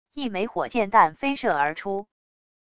一枚火箭弹飞射而出wav音频